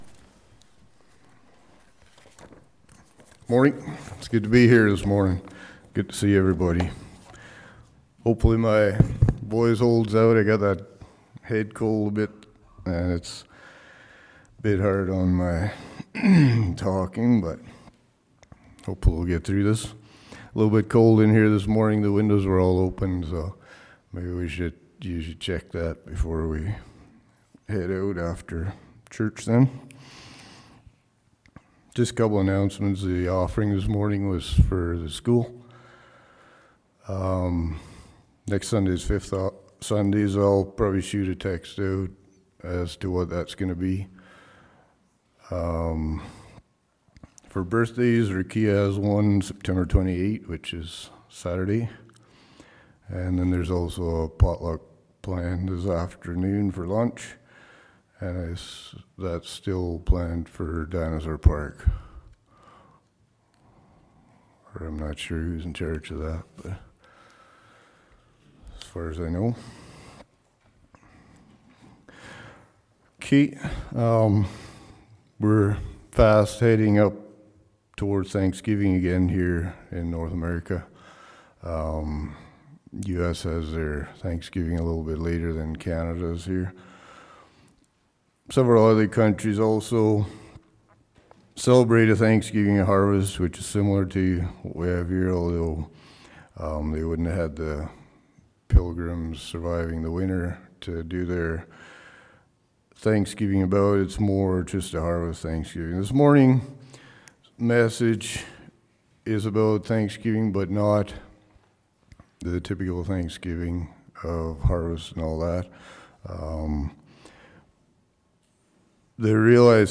Congregation: Grasslands